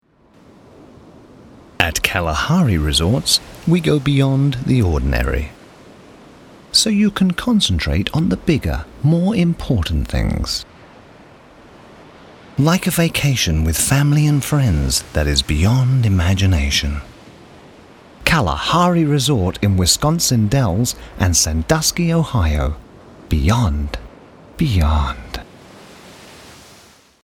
BRITISH ENGLISH DISTINCT ALL ENGLISH ACCENTS, SEXY, SMOOTH,DEEP, INVITING British voice, Classy, Conversational, informative, interesting, Commanding, Believable, Smooth, hard sell.
Sprechprobe: Sonstiges (Muttersprache):